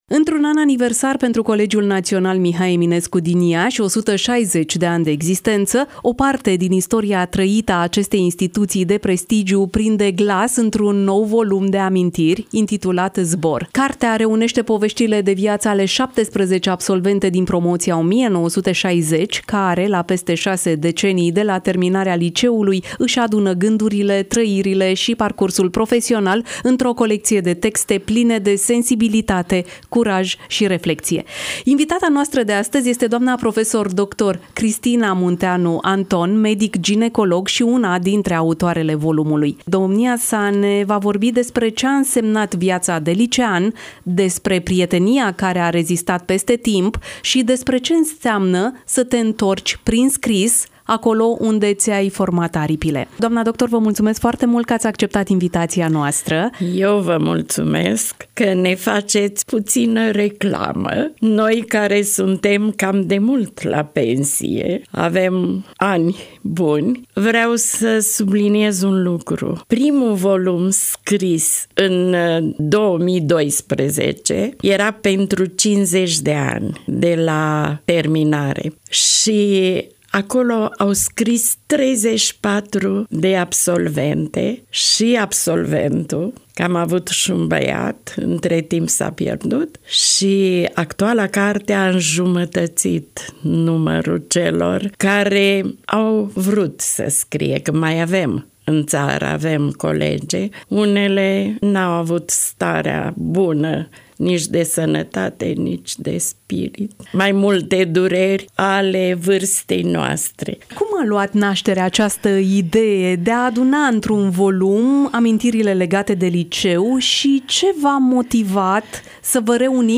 (INTERVIU) Iași: Absolventele promoției 1960 de la fostul Liceu ”Oltea Doamna”, actualul Colegiu ”Mihai Eminescu”, lansează un nou volum – document cu amintiri